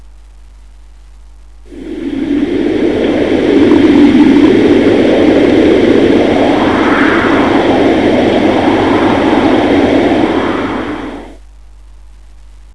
Sturm